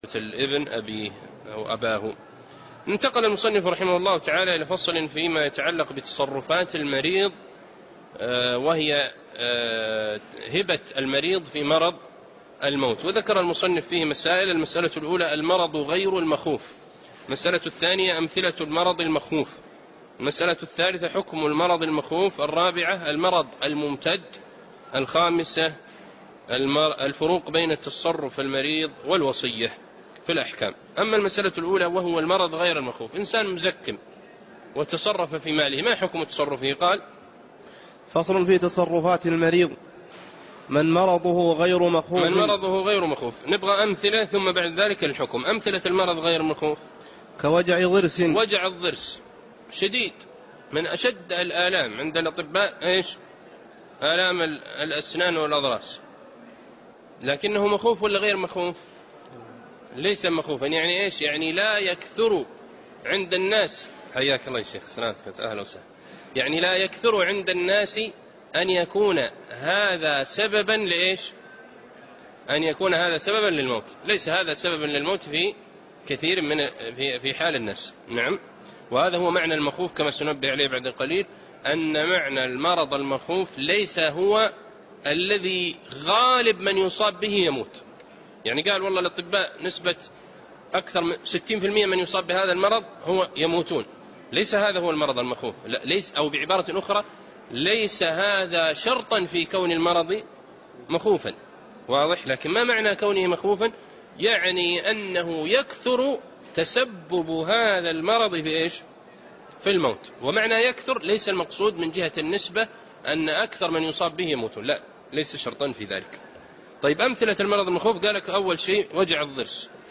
الدرس 101- شرح زاد المستقنع ( تابع باب الهبة والعطية )